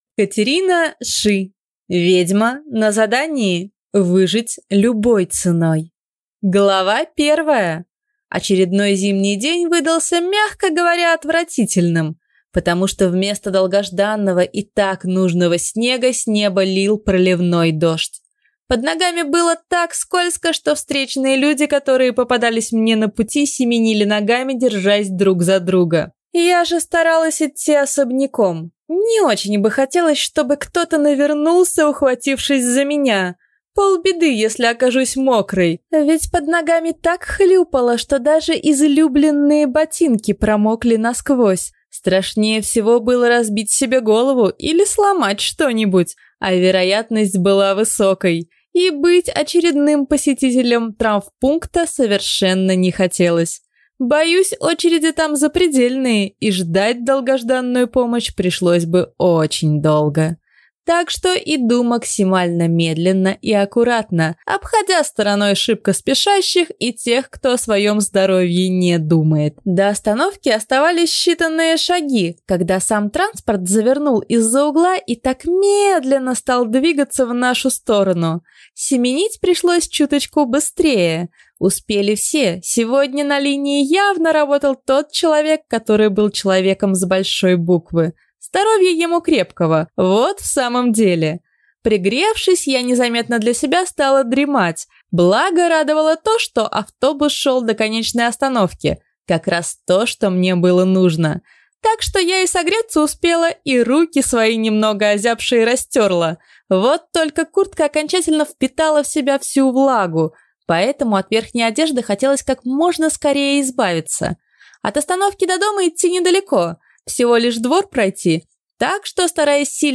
Аудиокнига Ведьма на задании. Выжить любой ценой | Библиотека аудиокниг